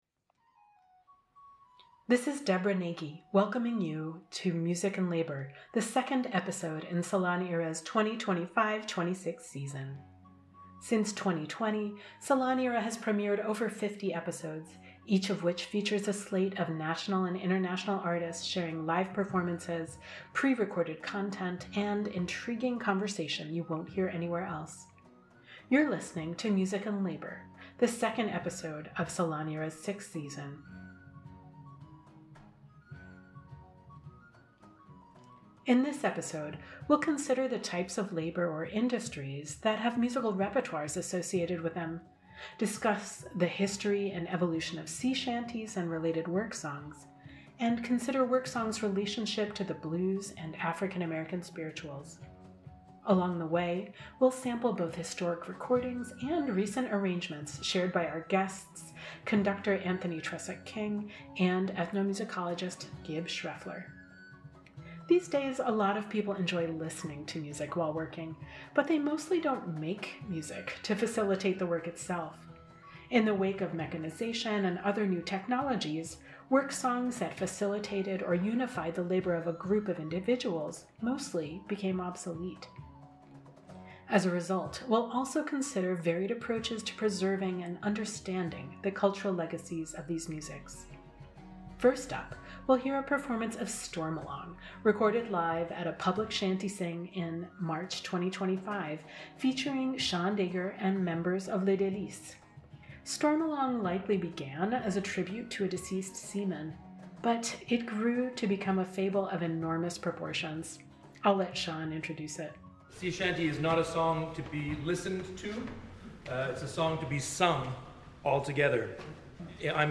Featuring live concert recordings